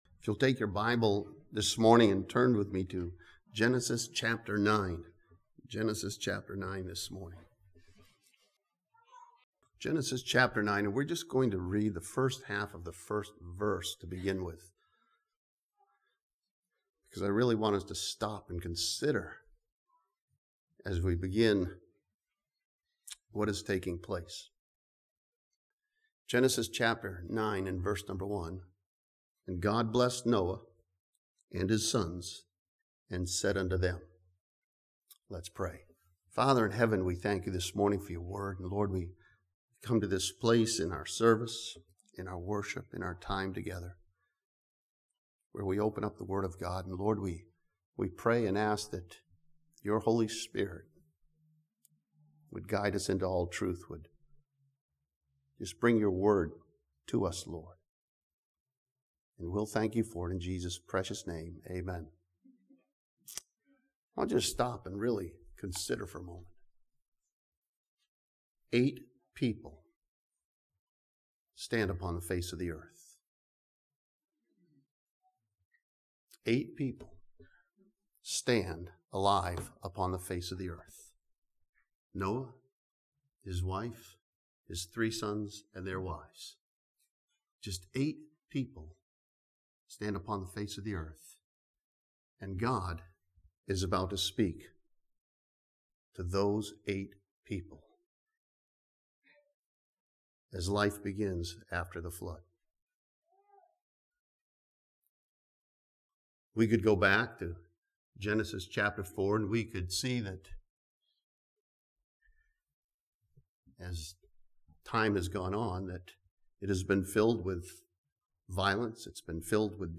This sermon from Genesis chapter 9 challenges believers to see the value of life that God has given.